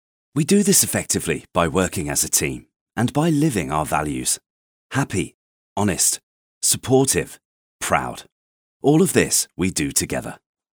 Male
Very versatile, warm, rich, authentic British accent, charismatic and friendly with a comedic twist of fun when needed!
Corporate
Trustworthy Business Gravitas
Words that describe my voice are British, Warm, Conversational.